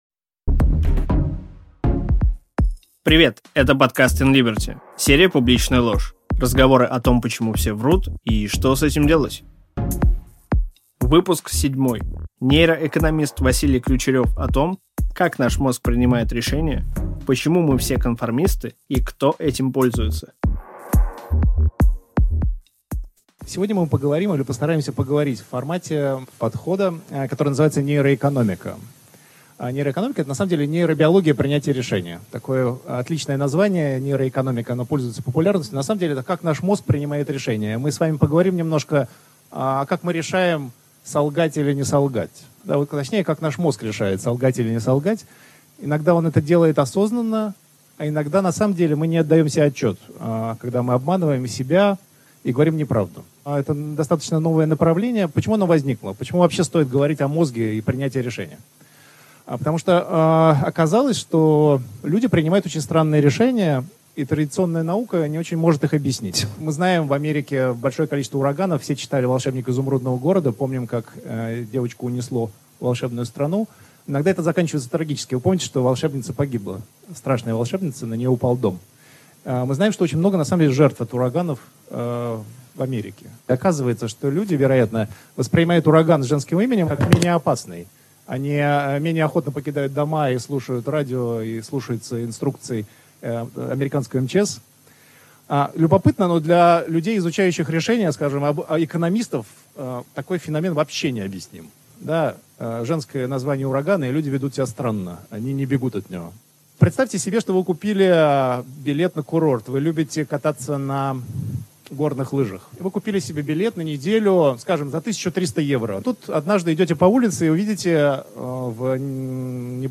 Аудиокнига Чужой мозг | Библиотека аудиокниг
Прослушать и бесплатно скачать фрагмент аудиокниги